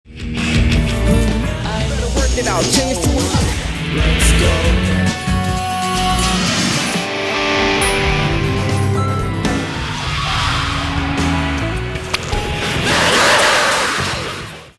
iTunes のミュージックライブラリを利用して、再生回数や割り当てたレイトなどの情報からその人の好みの曲を抽出してオーディオ署名を作成してくれる iTunes Signature Maker ってのがあるんだけど、試しに作成してみたら超イントロクイズドレミファドン！なミキシングぶりに軽くのけぞった。